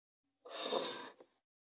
2025年2月19日 / 最終更新日時 : 2025年2月19日 cross 効果音
392,机の上をスライドさせる,スー,ツー,サッ,シュッ,スルッ,ズズッ,スッ,コト,トン,ガタ,ゴト,カタ,ザッ,